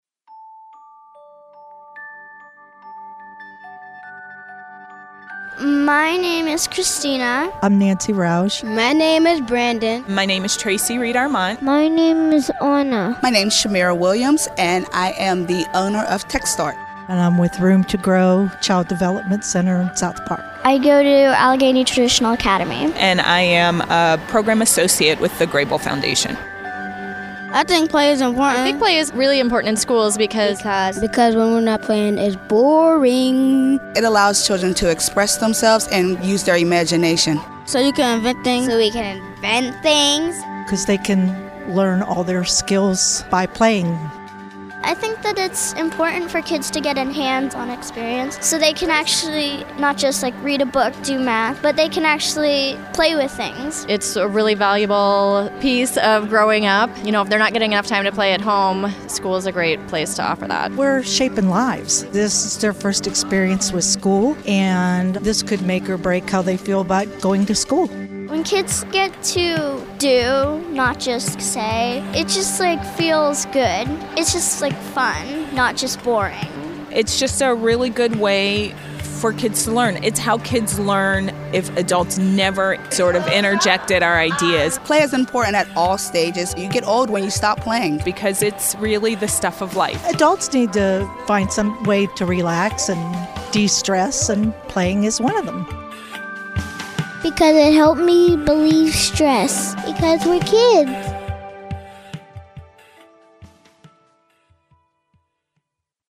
Listen below to hear conference attendees weigh in on the conference itself, and kids and adults alike on the importance of Play!